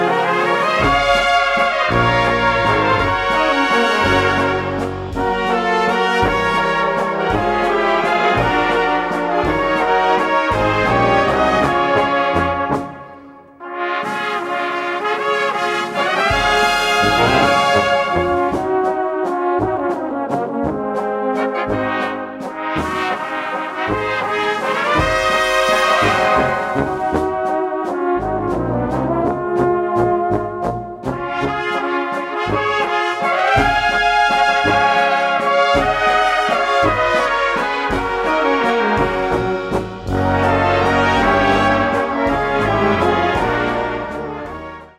Gattung: Walzer für Blasorchester
Besetzung: Blasorchester